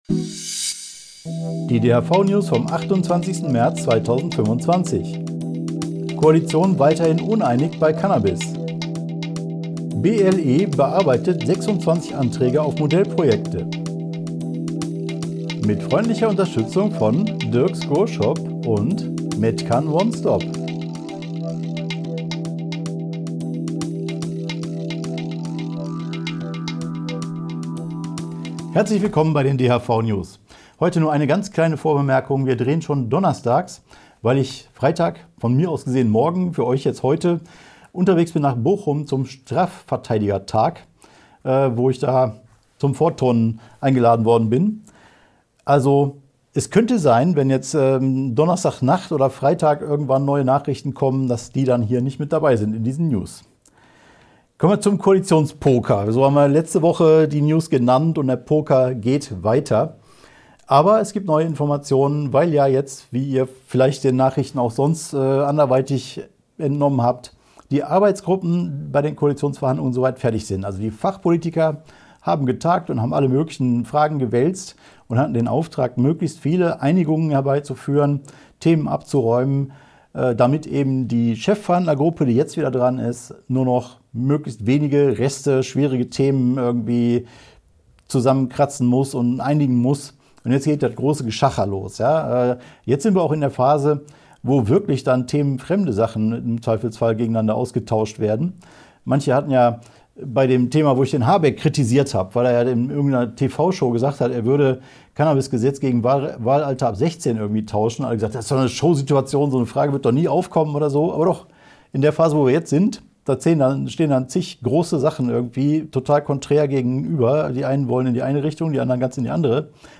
DHV-News # 460 Die Hanfverband-Videonews vom 28.03.2025 Die Tonspur der Sendung steht als Audio-Podcast am Ende dieser Nachricht zum downloaden oder direkt hören zur Verfügung.